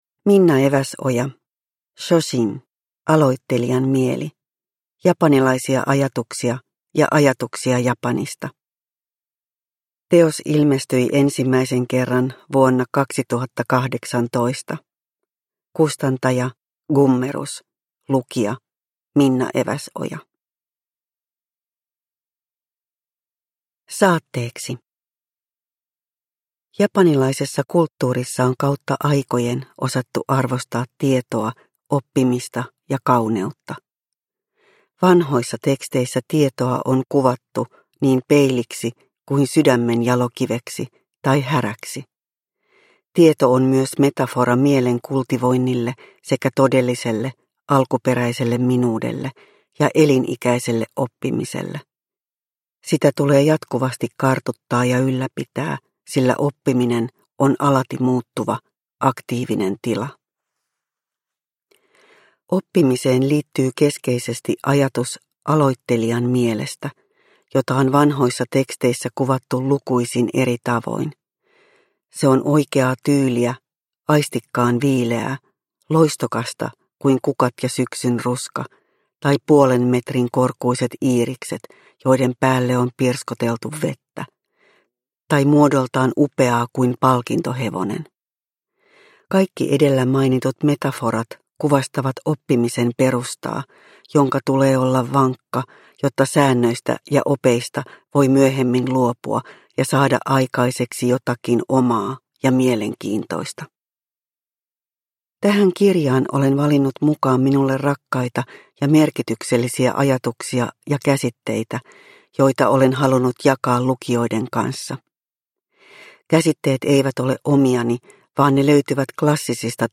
Shoshin - aloittelijan mieli – Ljudbok – Laddas ner